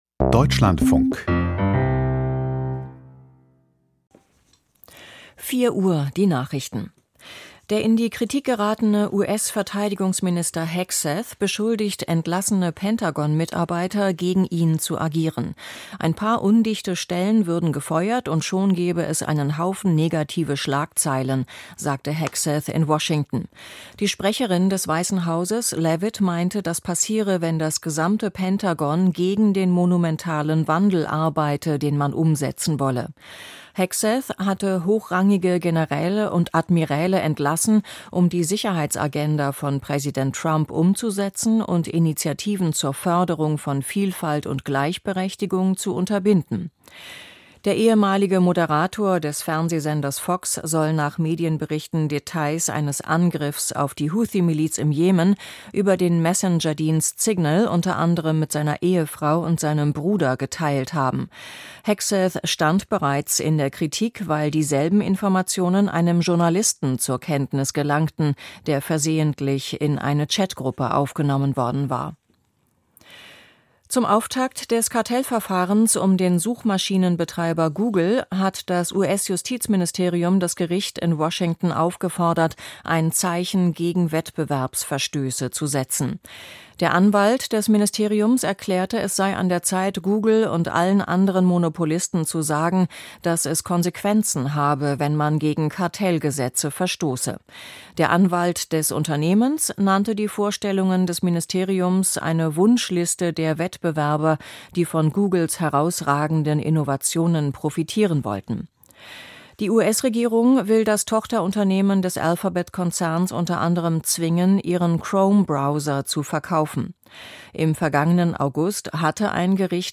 Die Deutschlandfunk-Nachrichten vom 22.04.2025, 04:00 Uhr